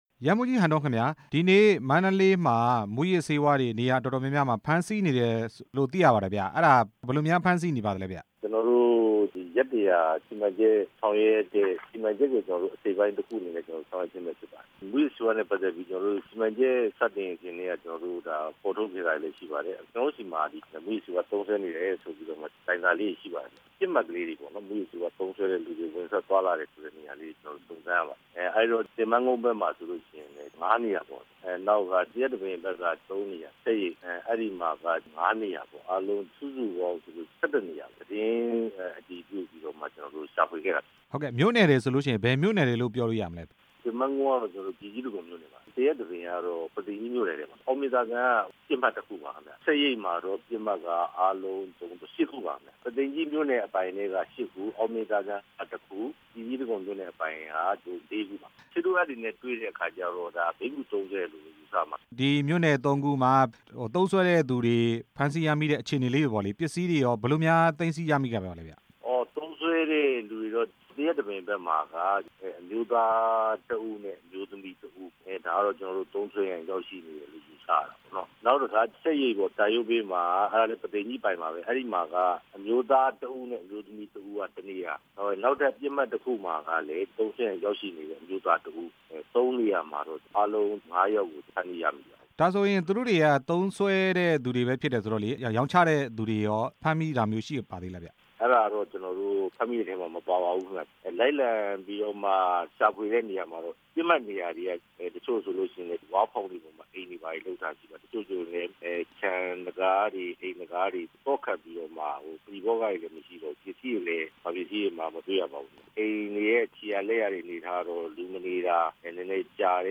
ဆက်သွယ်မေး မြန်းထားပါတယ်။